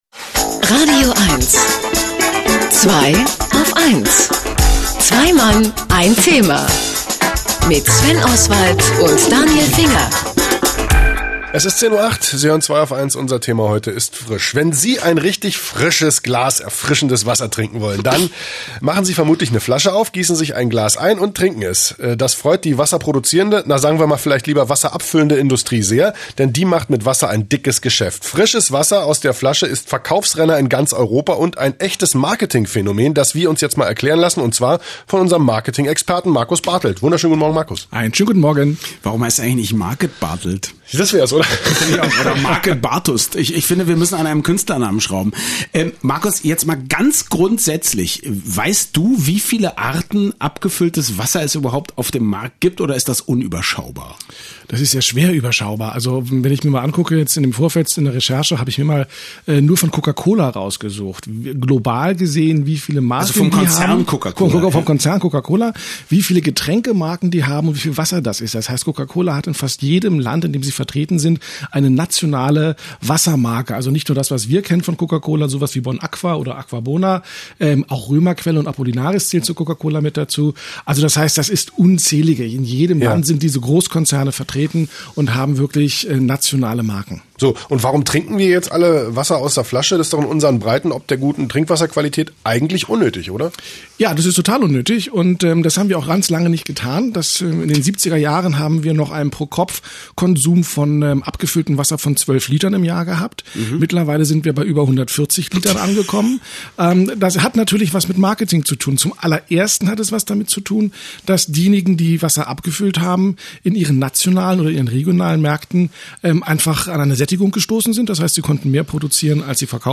Wie es dazu kam, was für ein Riesengeschäft dahintersteckt und wie Großkonzerne hier profitorientiert  jenseits jeglicher Ethik und Moral agieren, das war das Thema meines heutigen Interviews bei „Zweiaufeins“ in „radioeins„, das hier nachgehört werden kann: